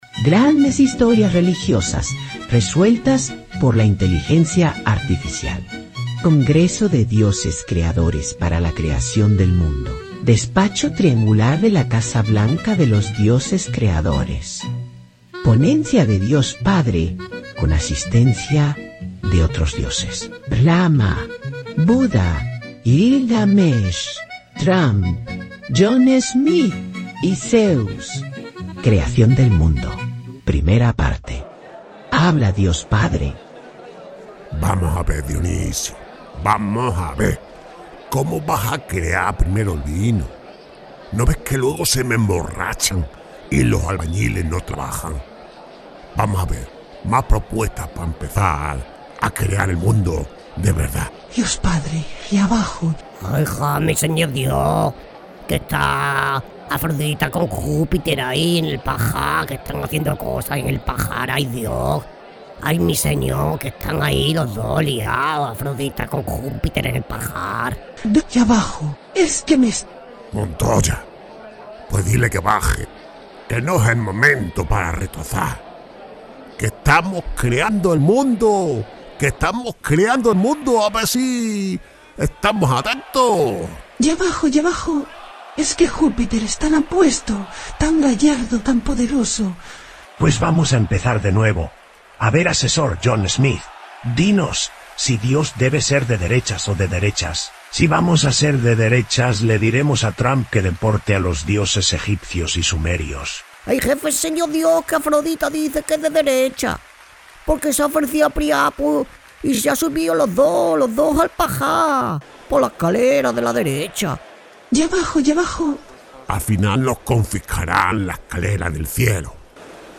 Locutores profesionales.
Spanish voice over.
creacion-mundo-podcast-humor.mp3